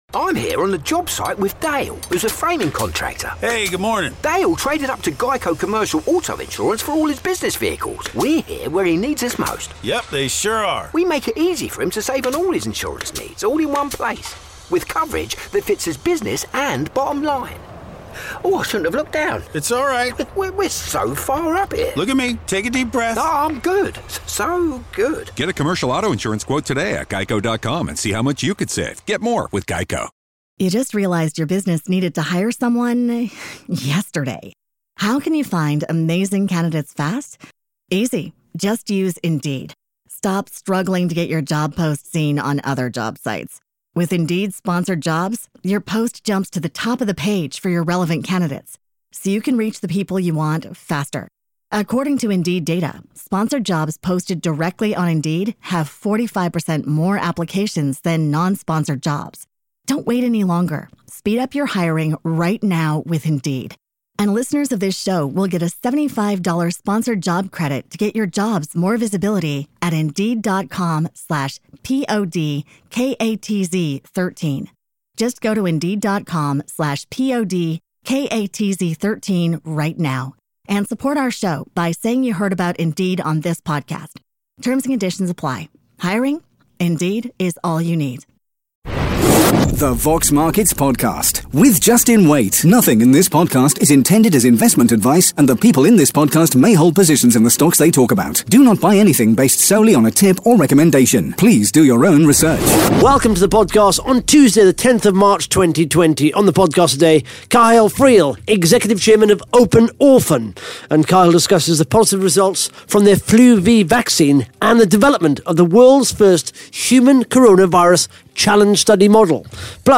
(Interview starts at 9 minutes 5 seconds)